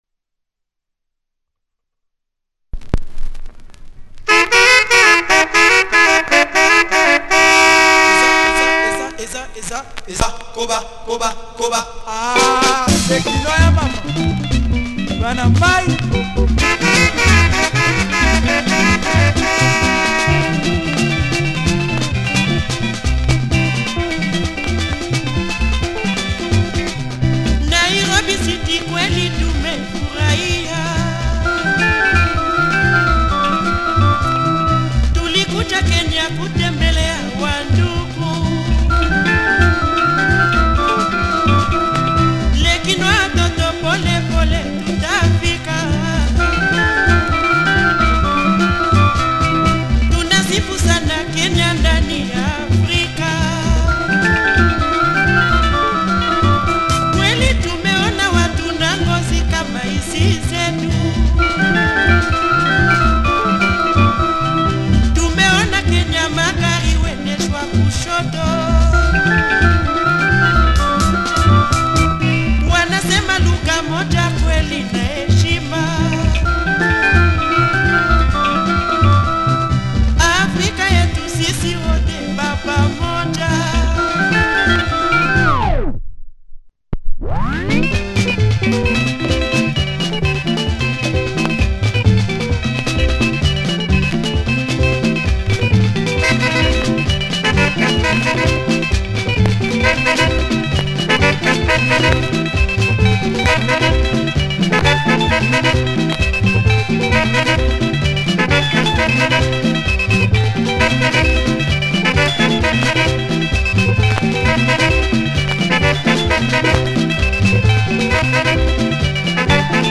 Lingala track
nice keys too.